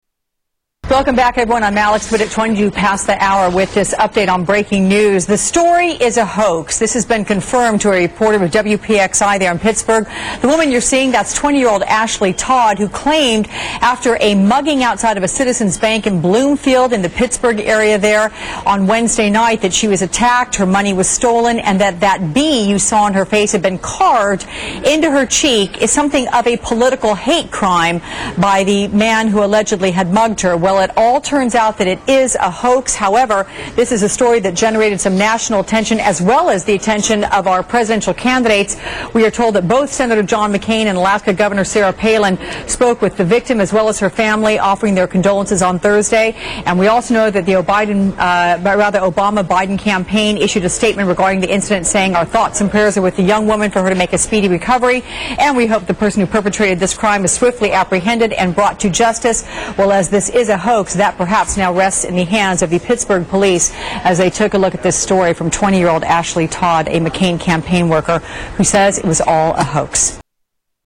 McCain Volunteer Created Race Baiting Attack- MSNBC News